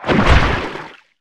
Sfx_creature_spikeytrap_latch_01.ogg